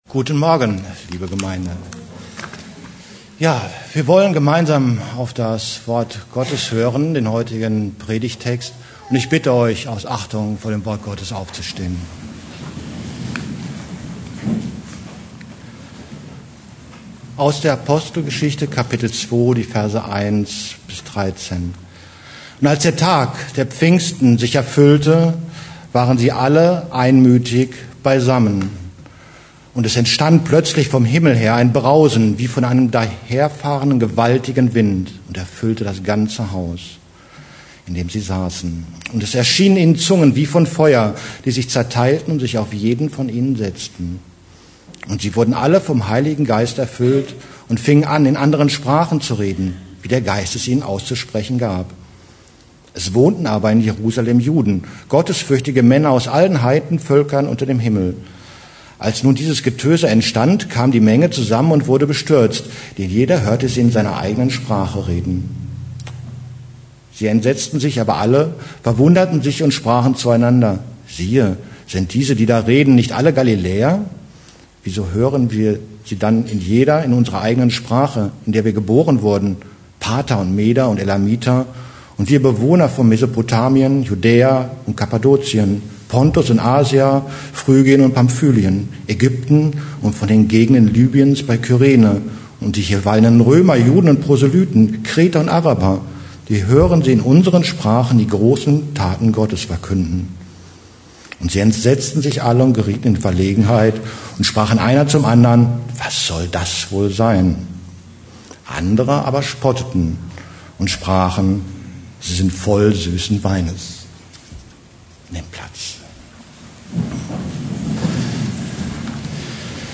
Preacher
Einzelpredigten